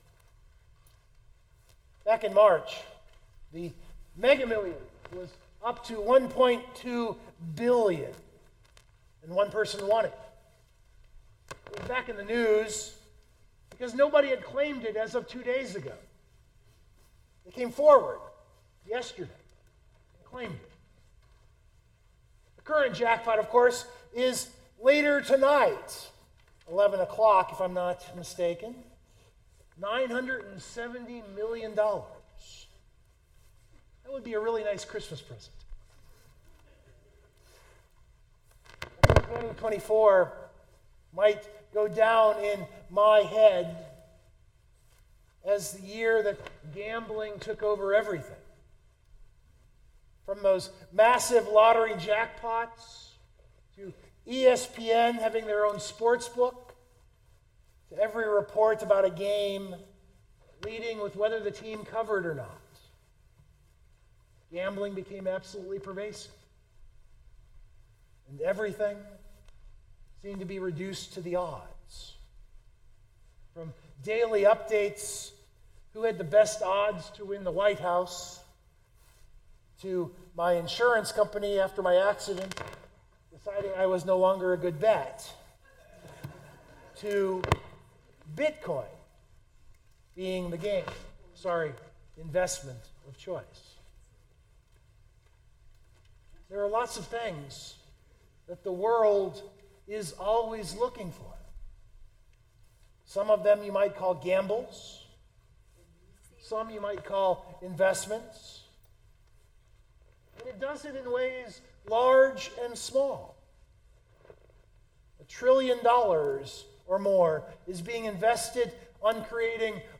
Christmas Eve 2024
That’s something of the theme of this homily. It is possible to look at the biblical story through gambler’s eyes.